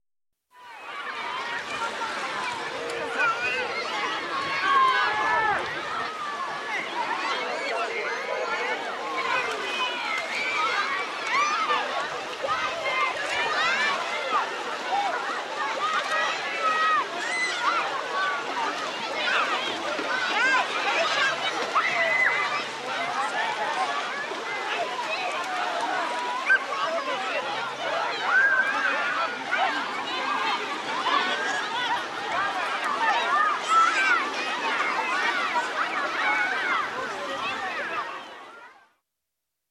На этой странице собраны натуральные звуки пляжа: шум волн, легкий бриз, детский смех на песке и другие уютные аудиофрагменты.
Шум отдыхающих на пляже